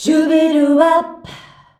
SHUBIWAP E.wav